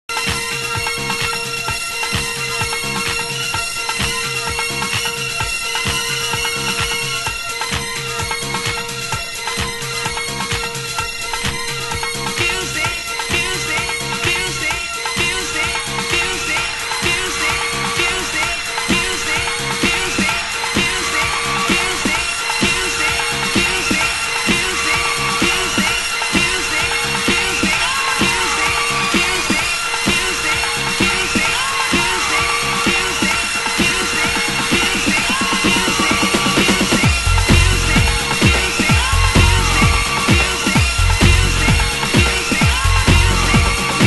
空を切るようなサウンドに加工されたシンセとDISCOネタが絡むフロアキラー！